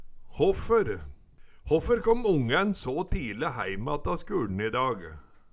håffer - Numedalsmål (en-US)